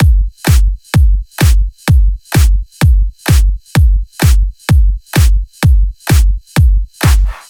VDE1 128BPM Speedy Kit
VDE 128BPM Speedy Drums 1.wav